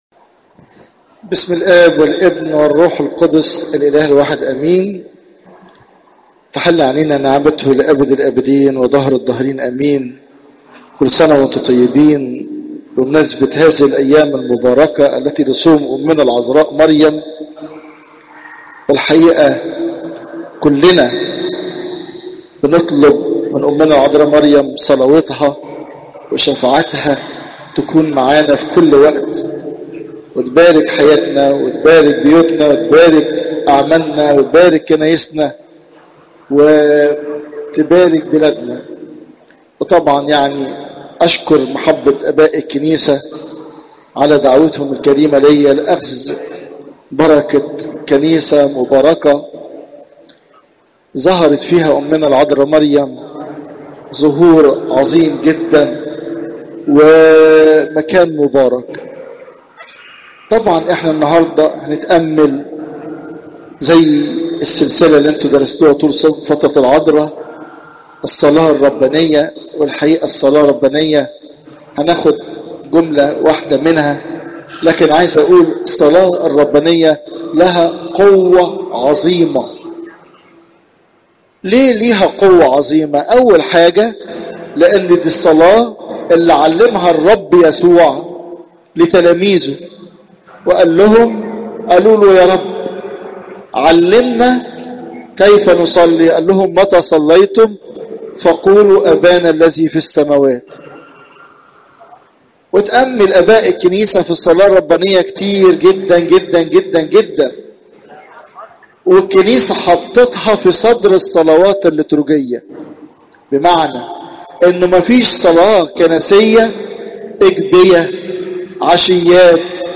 عظات نهضة صوم العذراء